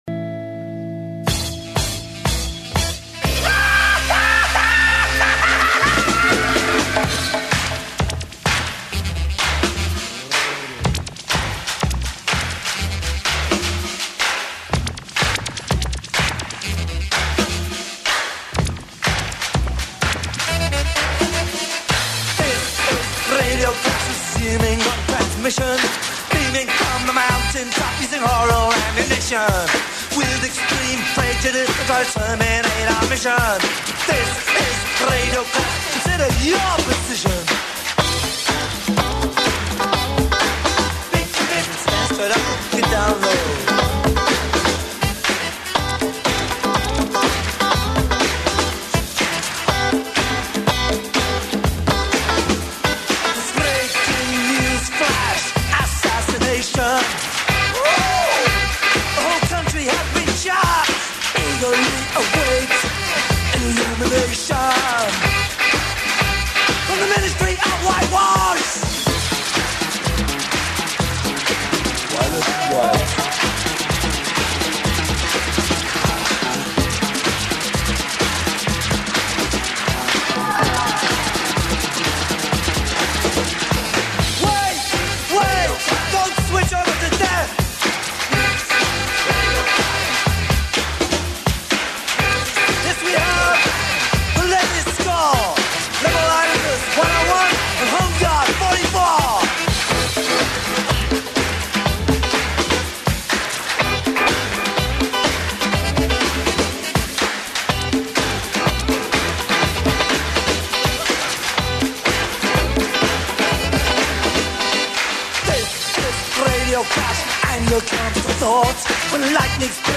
a great chat about his comix.